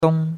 dong1.mp3